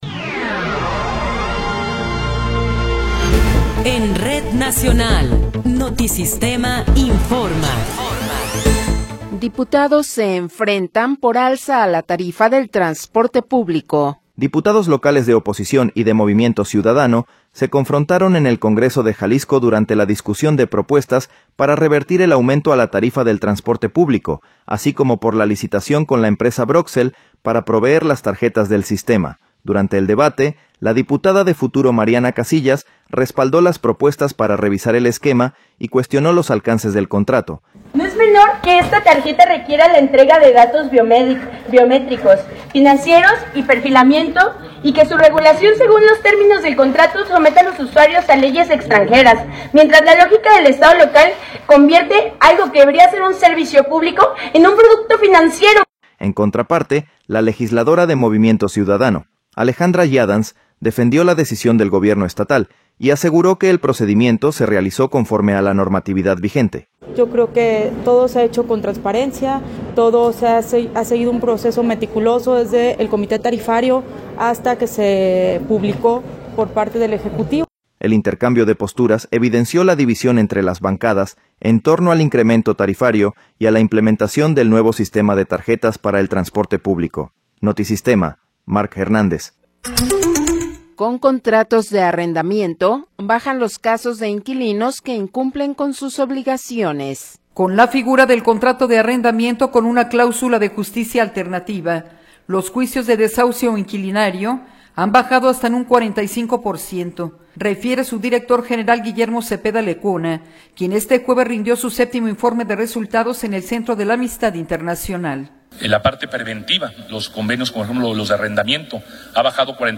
Noticiero 15 hrs. – 15 de Enero de 2026